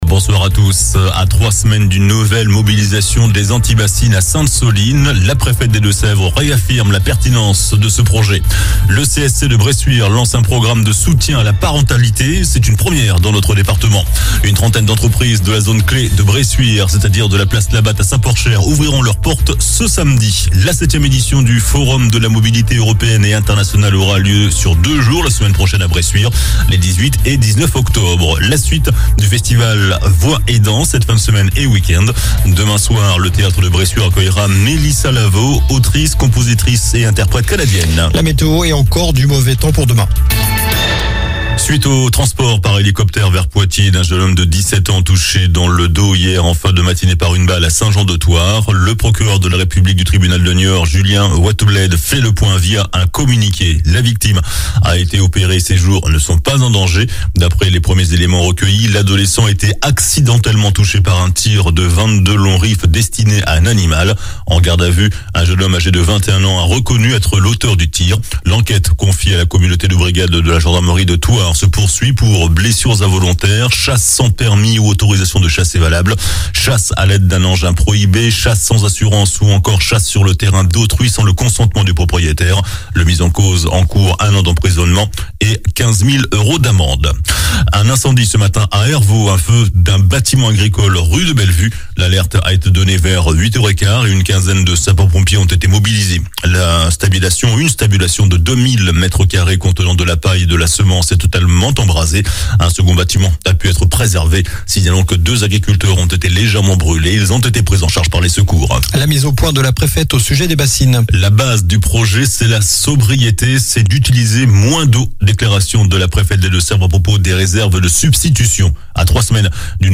JOURNAL DU JEUDI 13 OCTOBRE ( SOIR )